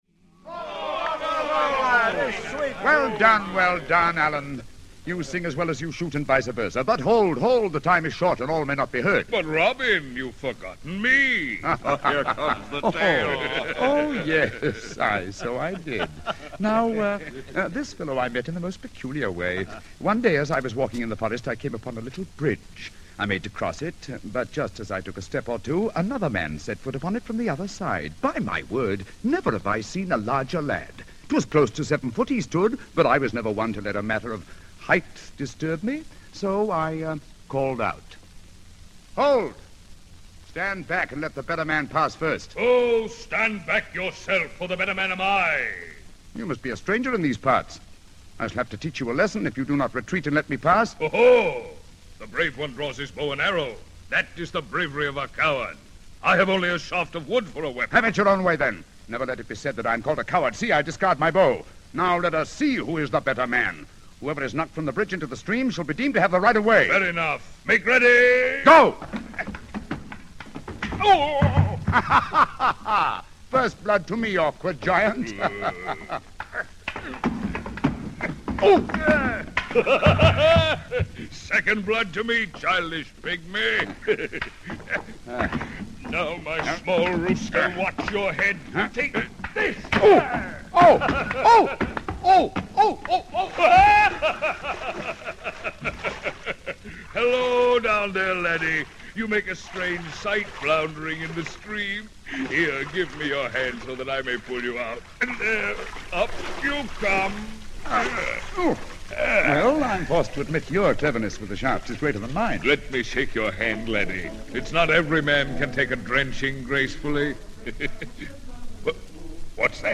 Robin Hood - Basil Rathbone as Robin Hood (1 record, 33.3 rpm, Columbia ML 2063), recorded in 1949